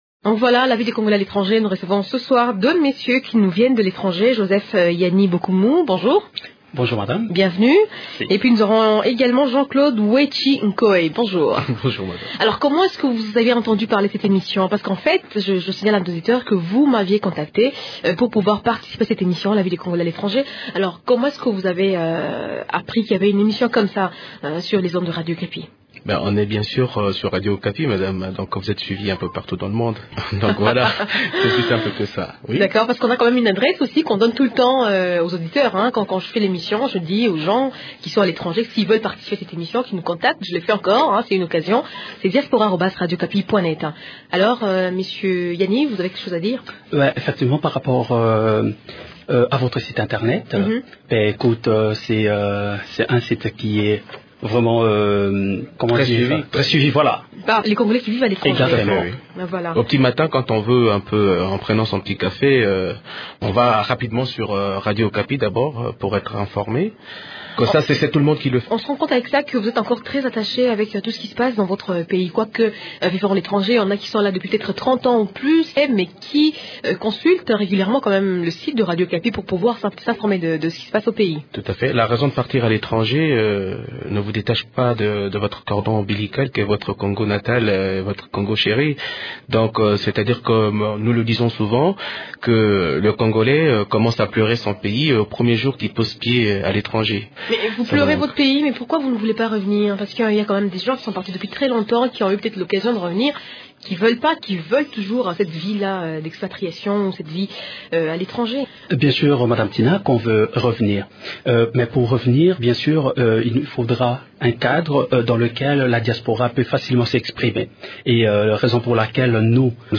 Ecoutez-les dans cet entretien au studio de Radio Okapi à Kinshasa